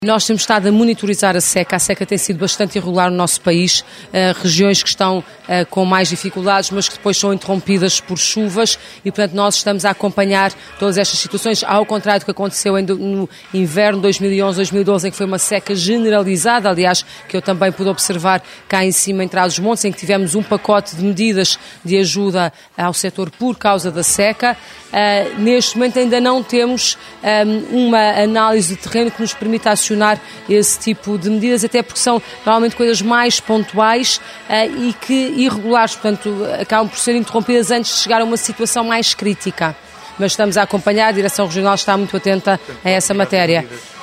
Declarações da Ministra da Agricultura, Assunção Cristas, que sexta-feira falou em Macedo de Cavaleiros.